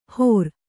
♪ hōr